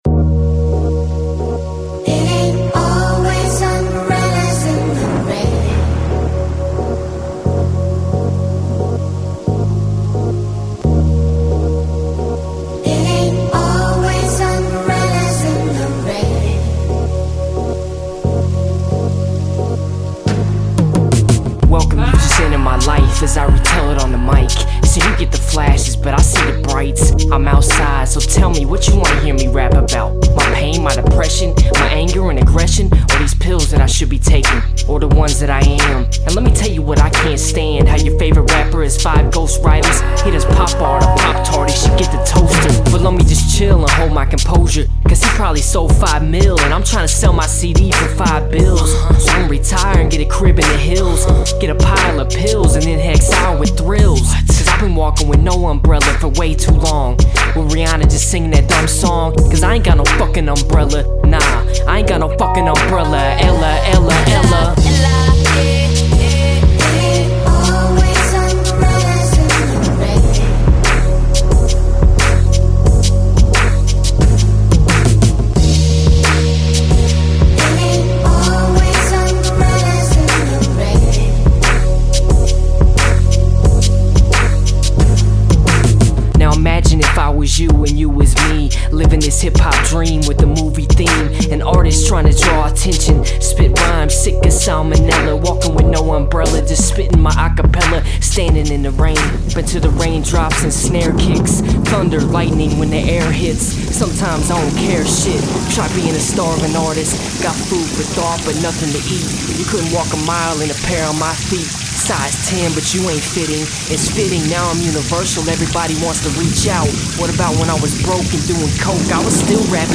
Determined